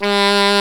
Index of /90_sSampleCDs/Roland L-CD702/VOL-2/SAX_Alto Short/SAX_Pop Alto
SAX F#2 S.wav